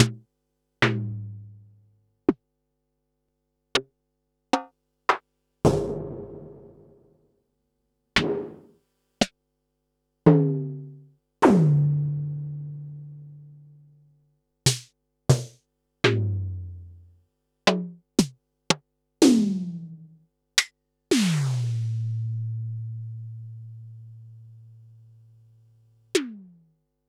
Pearl_SC-40_Snaredrum.wav